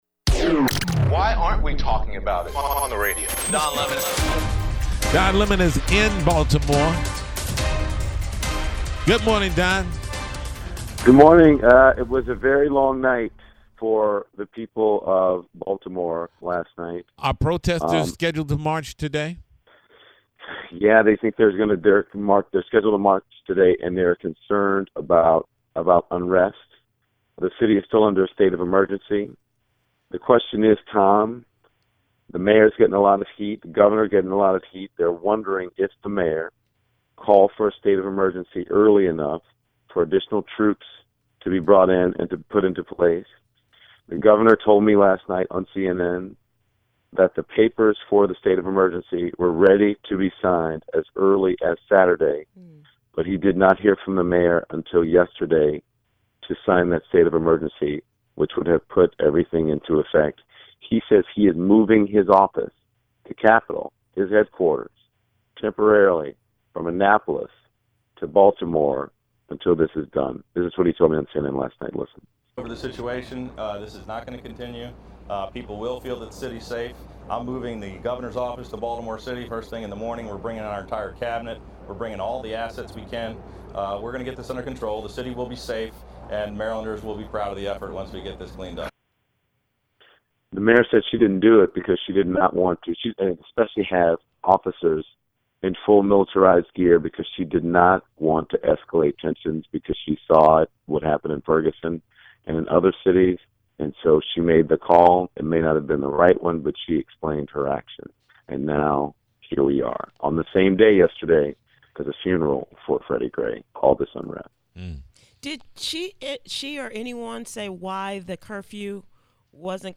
Don Lemon Is Live From Baltimore: Did The Mayor Call For State Of Emergency Soon Enough?